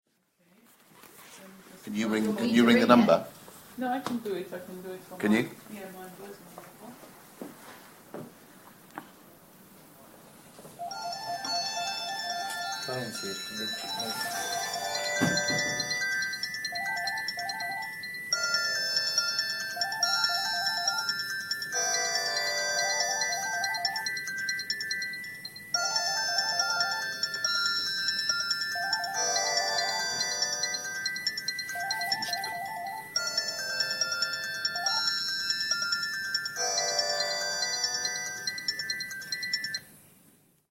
phone ringtone chords